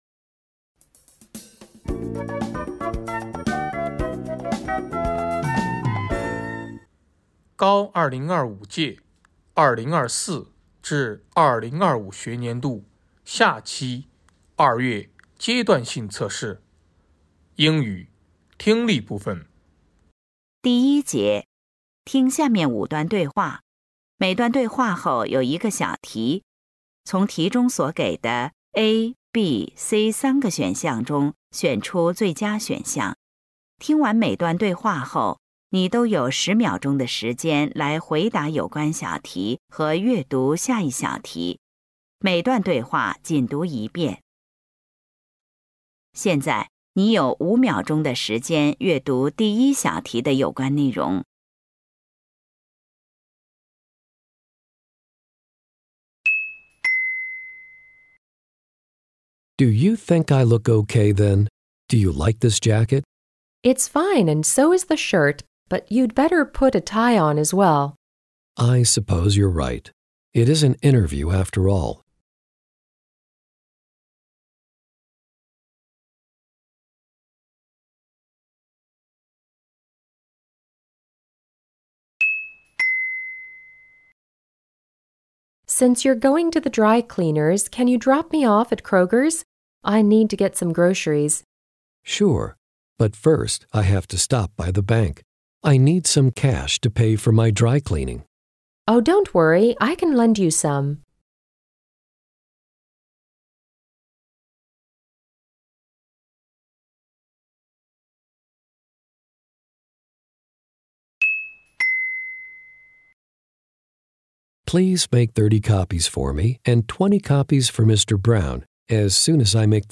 成都七中2025届高三下期入学考试英语听力.mp3